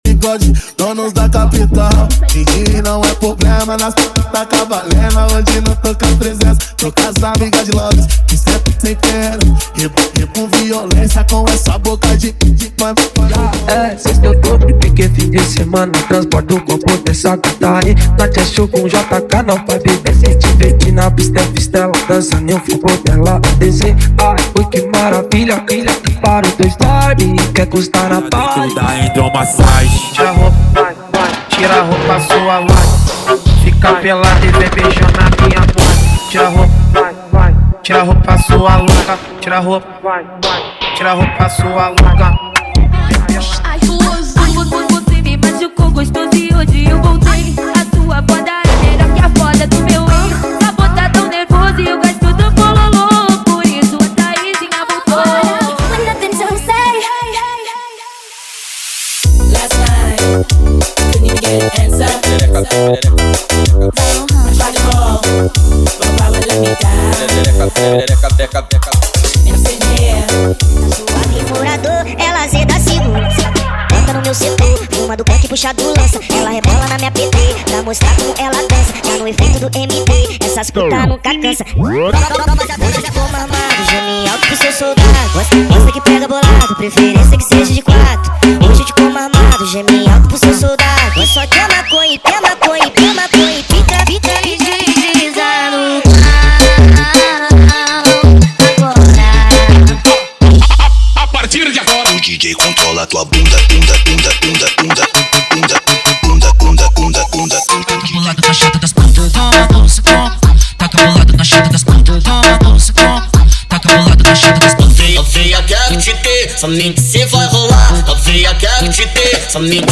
• Brega Funk = 100 Músicas
• Sem Vinhetas
• Em Alta Qualidade